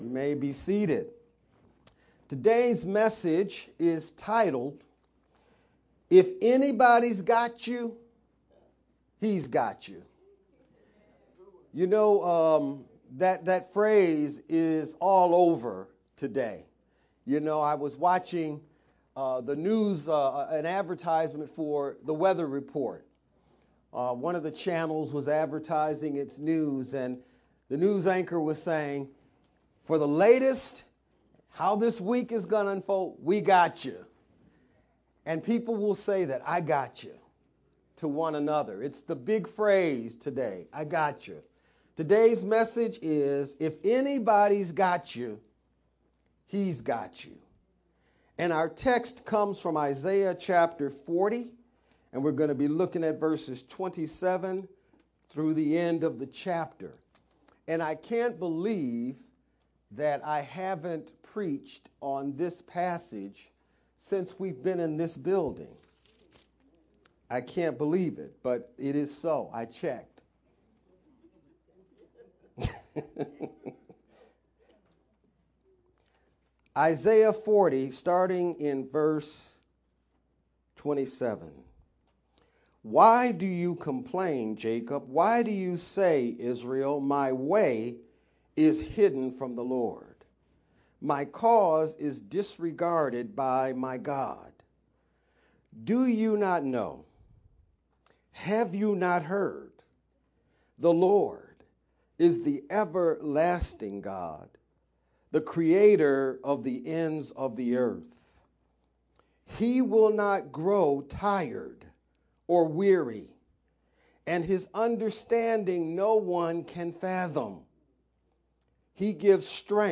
April-14th-Sermon-only1_Converted-CD.mp3